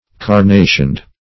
\Car*na"tioned\